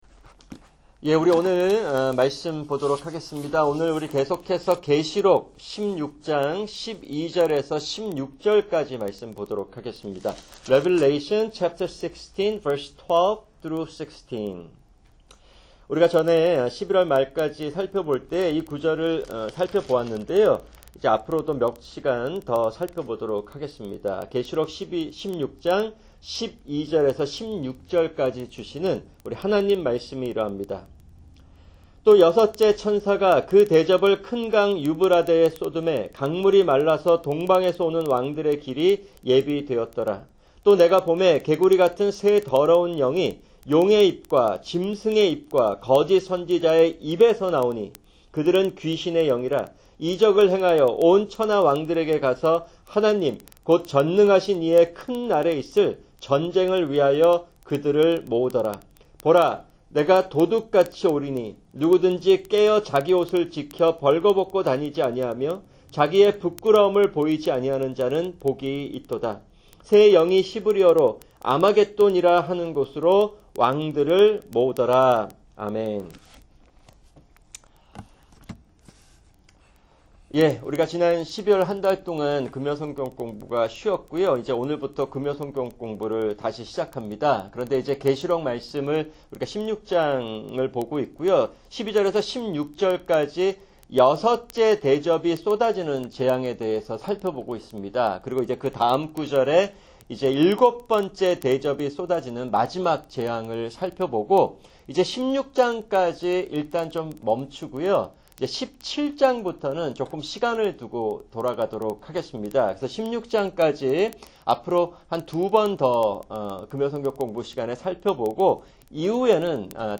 [금요 성경공부] 계시록 16:12-16(4)